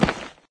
grassstone.ogg